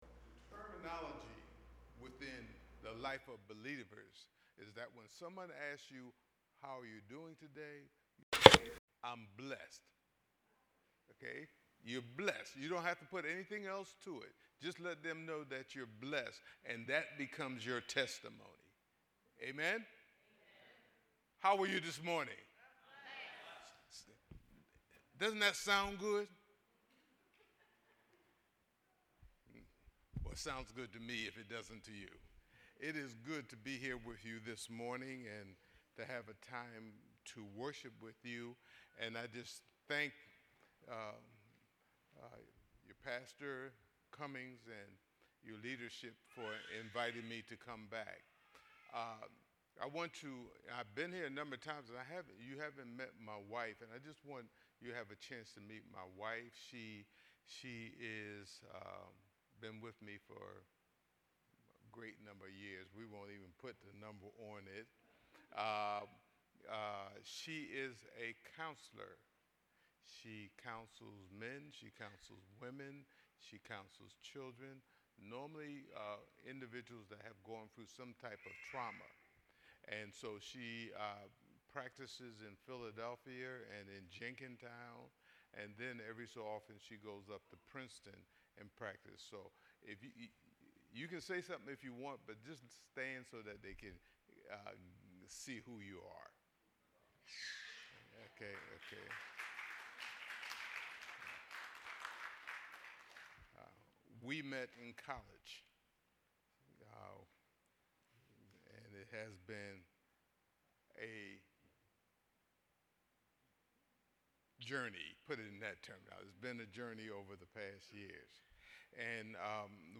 New Sermon: Life Pattern of Faith: Romans 4:20